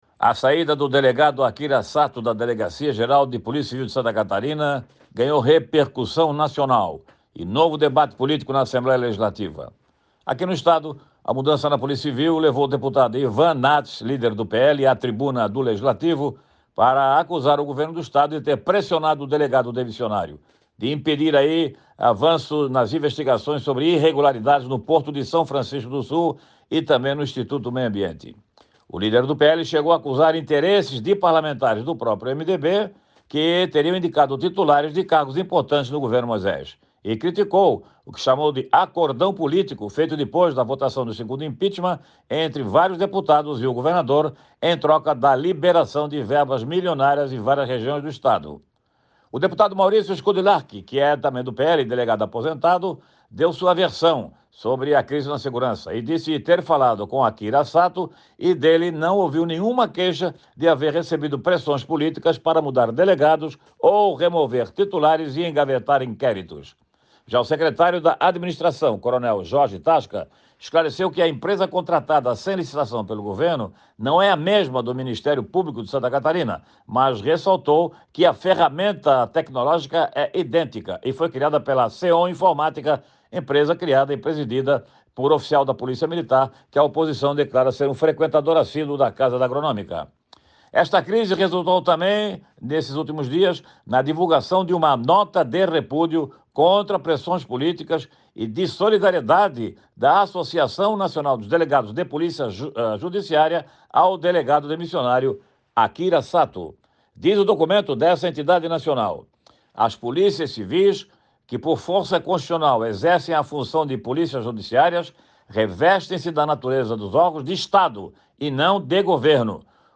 O jornalista comenta as visões sobre a demissão do delegado Akira Sato e as demonstrações de solidariedade ao ex-chefe da Delegacia Geral de Santa Catarina